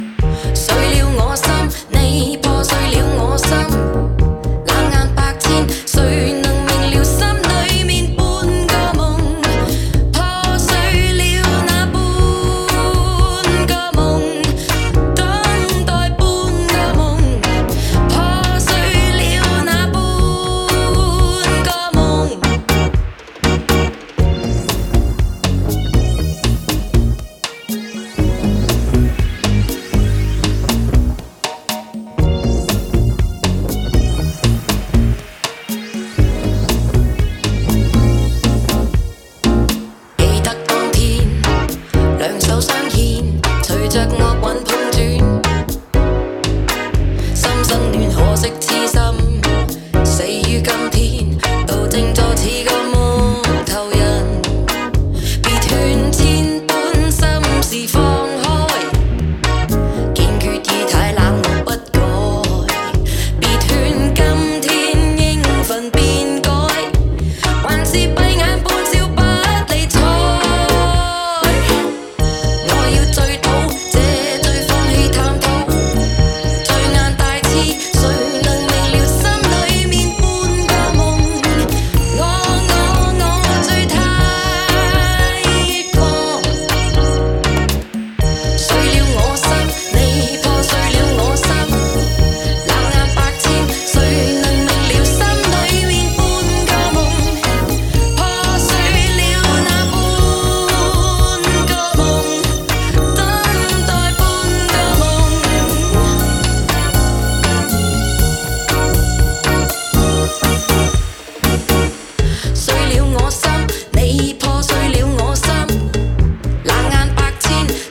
delivering a slick, dreamy dancefloor cut.
Genre: Electronic, Pop
Style: Cantopop, Balearic